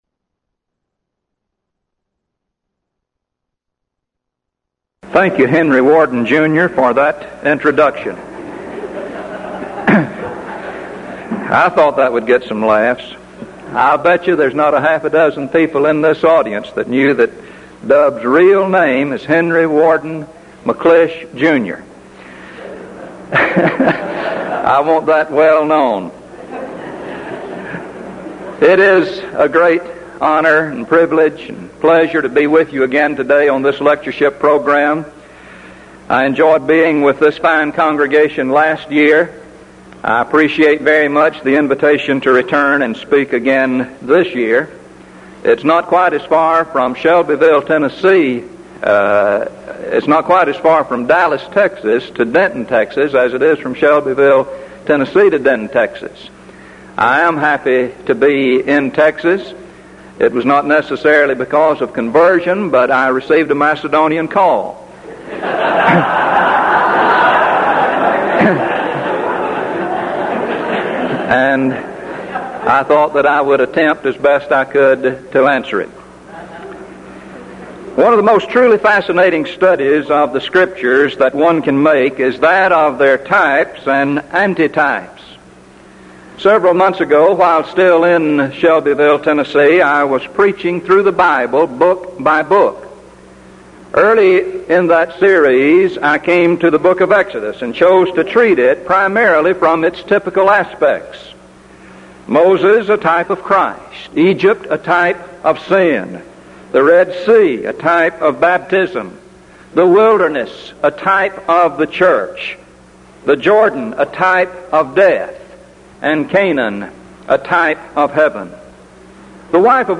Event: 1983 Denton Lectures Theme/Title: Studies in Hebrews
lecture